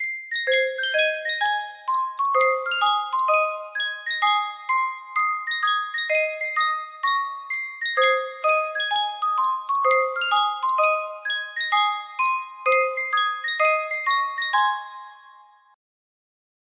Sankyo Music Box - Turkey in the Straw PW music box melody
Full range 60